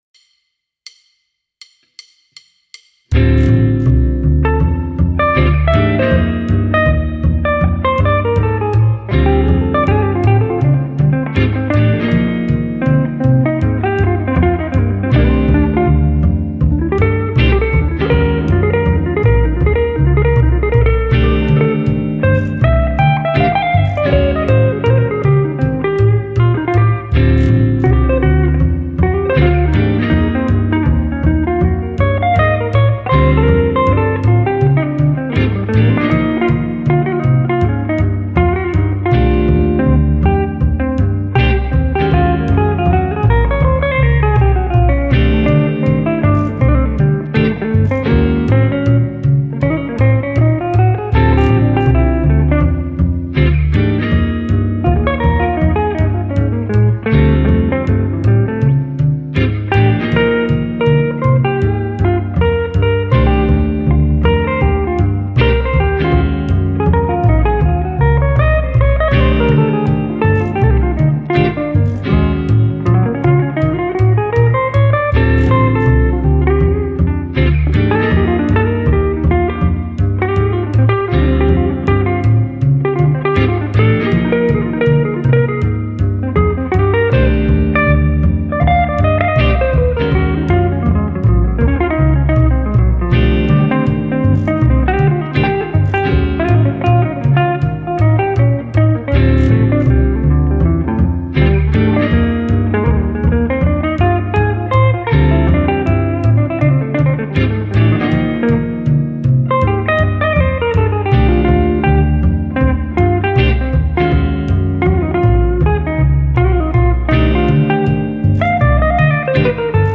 Full Jazz Jam Modal Jazz No.2.mp3